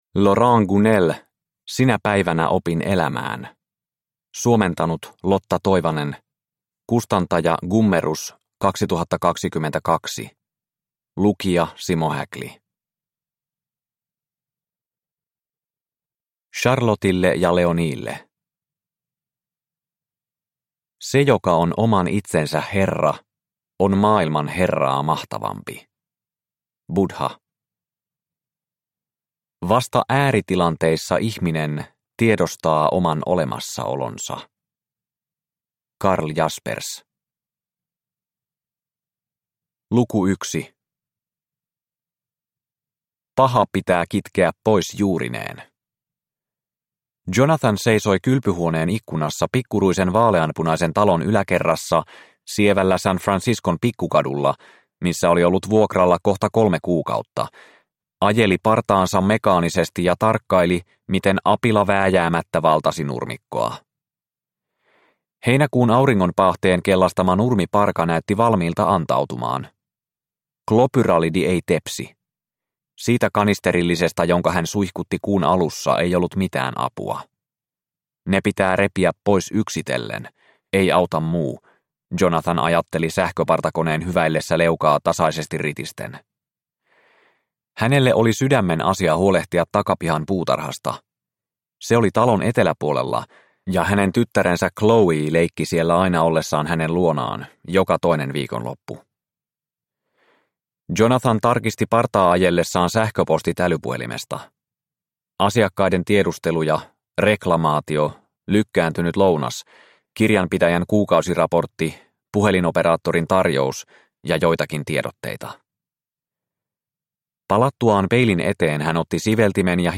Sinä päivänä opin elämään – Ljudbok – Laddas ner